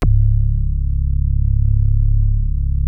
P.5 C#2 8.wav